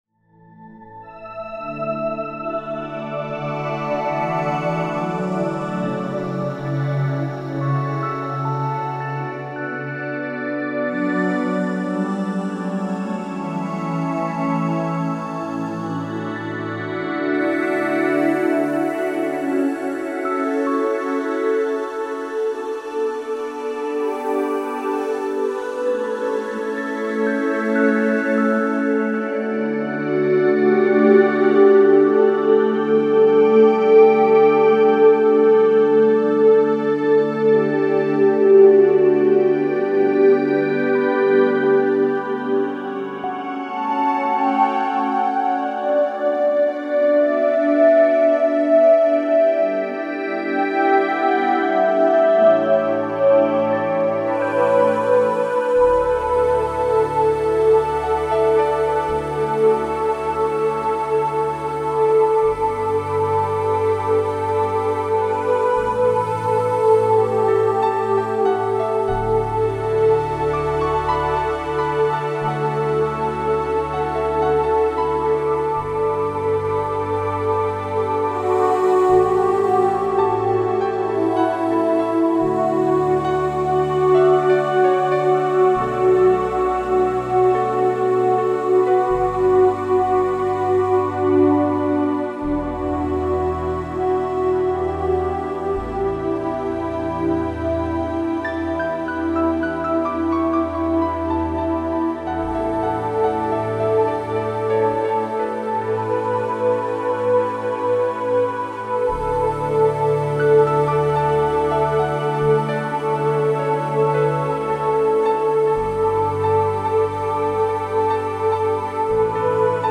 Meditation Song Excerpt: